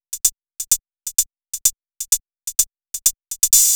VTDS2 Song Kit 06 Pitched Sneaking On The DF Hihats.wav